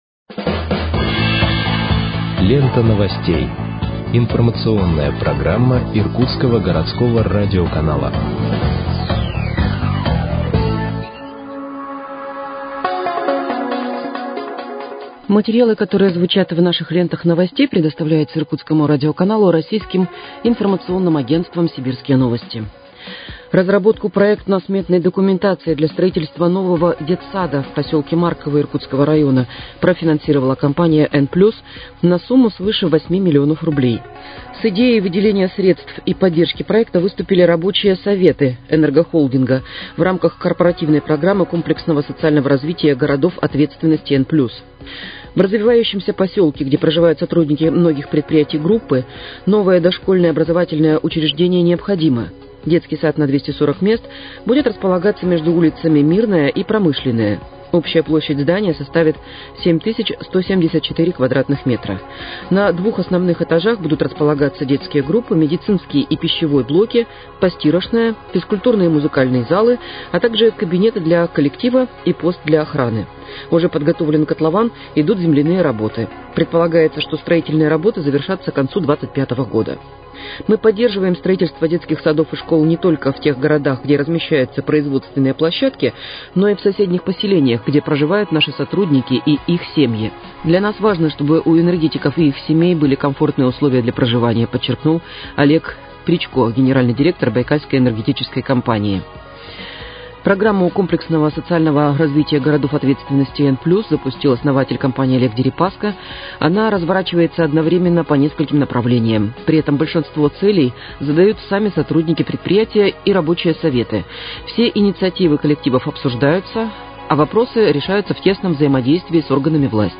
Выпуск новостей в подкастах газеты «Иркутск» от 27.11.2023 № 1